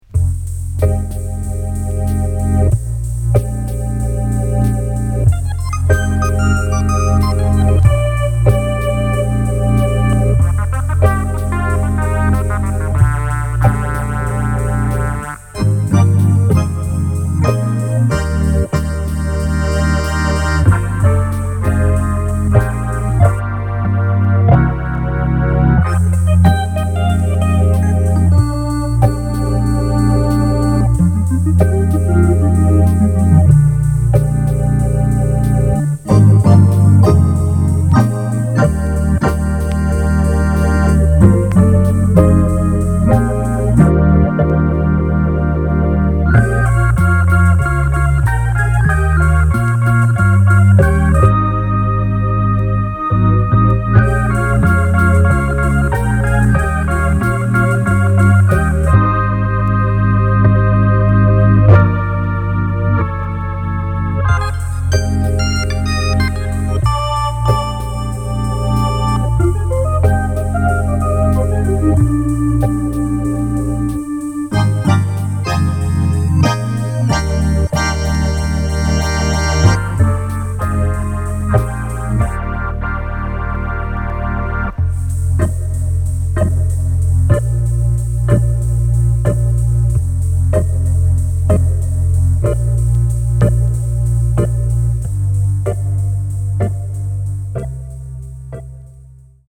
キーワード：電子音　ライブラリー　即興